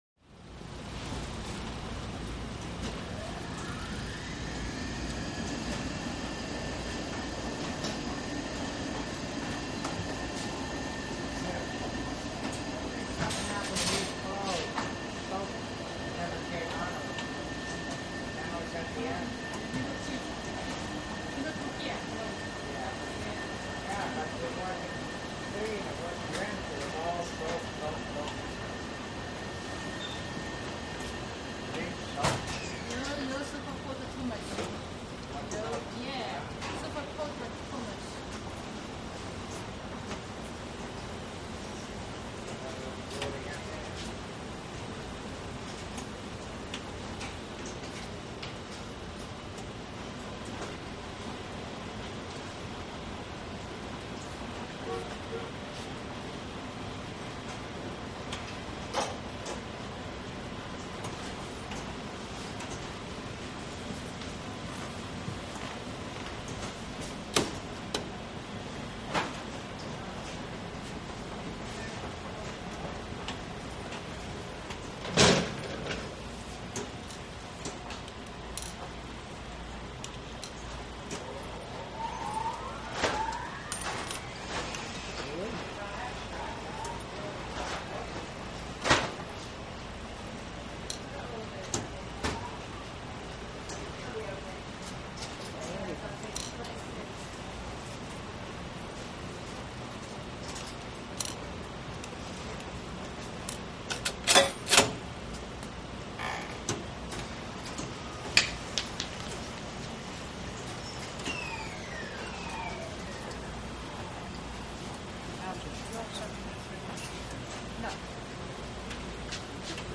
LaundromatAmbience CT102001
Laundromat Ambience; Hum And Whirr Of Washers And Dryers. Medium Walla Light And Sparse, Movement Clunks Of Doors Open And Close, Wind-ups And Wind Downs Of Machines. Insert Coins ( Index 02, 1; 43